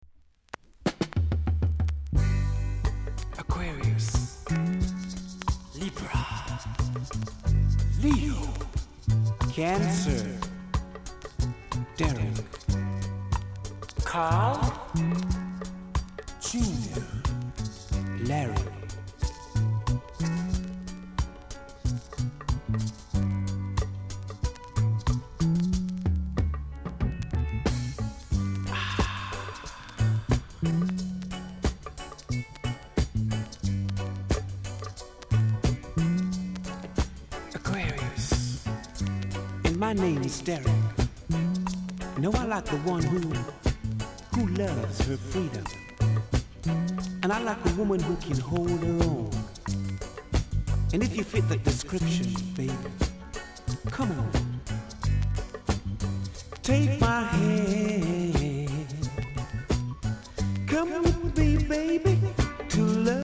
※出だしでパチノイズあります。